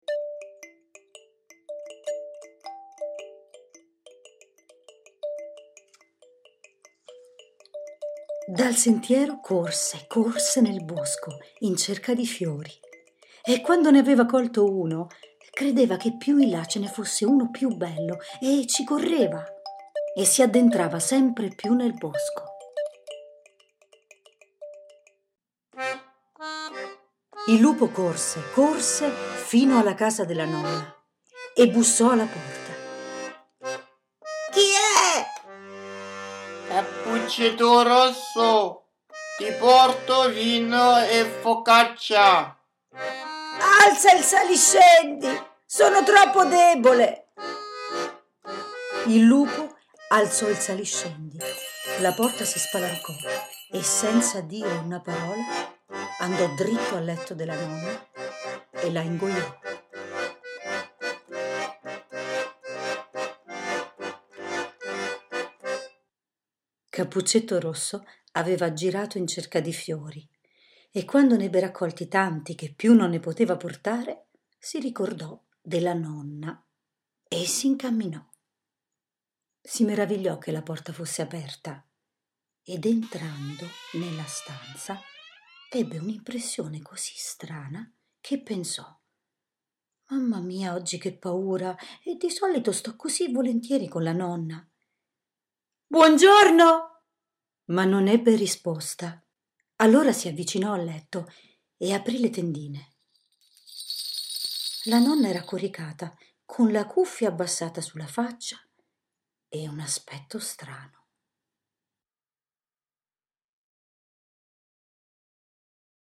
Storie tradizionali narrate con musica e canti + libretto illustrato
bozza_cappuccetto_rosso.mp3